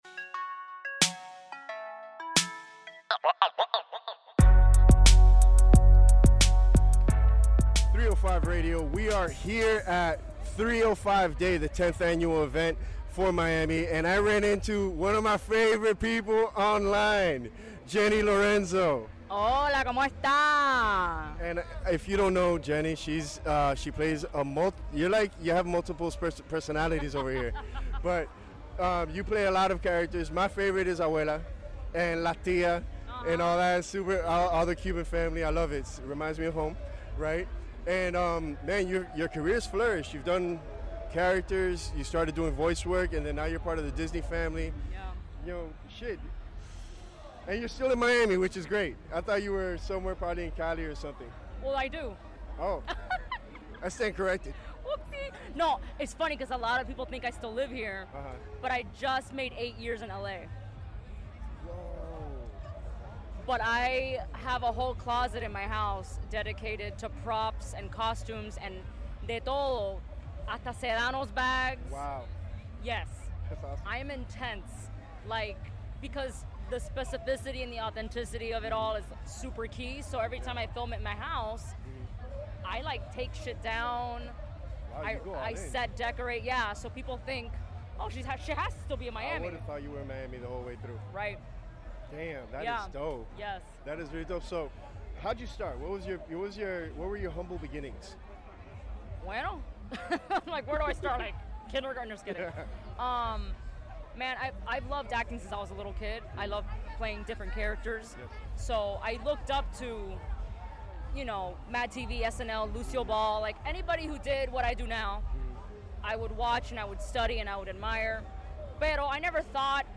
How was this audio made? March 5th 2023 aka 305 Day was a city wide event celebrating the culture, vibe, and music that makes Miami the place of legends. We had an amazing time covering events and the 305 Day event did not disappoint.